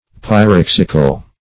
Search Result for " pyrexical" : The Collaborative International Dictionary of English v.0.48: Pyrexial \Py*rex"i*al\, Pyrexical \Py*rex"ic*al\, a. (Med.) Of or pertaining to fever; feverish.
pyrexical.mp3